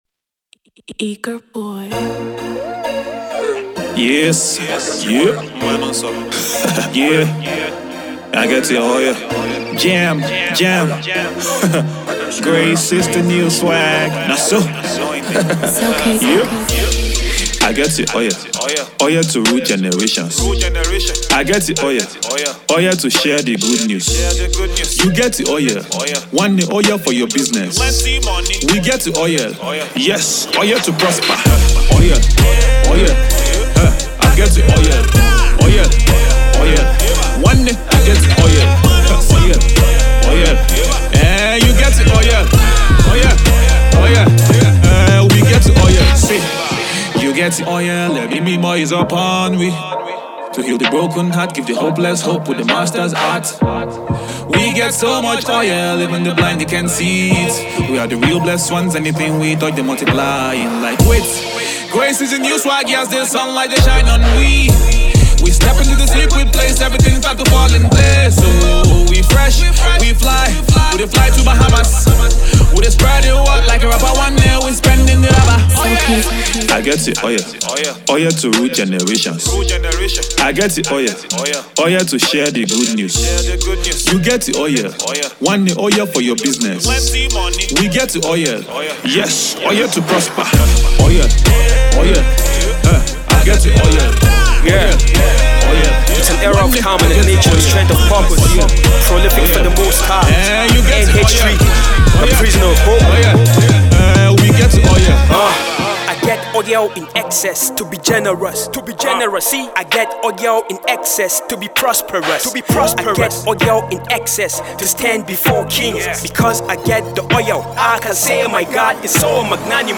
spirit filled/Inspired song